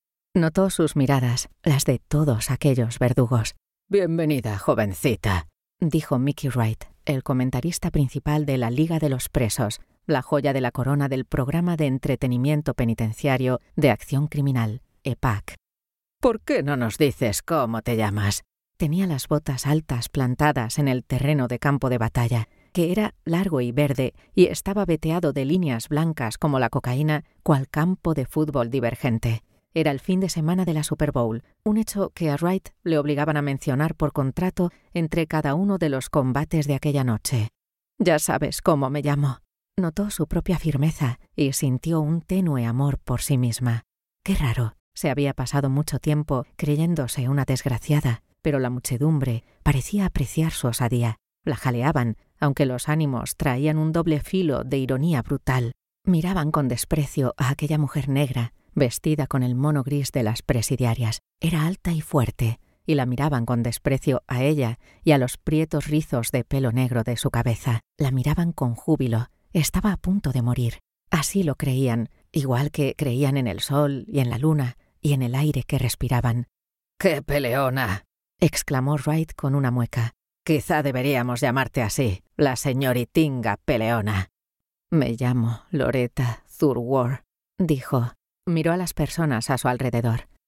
0714audiolibro_presos.mp3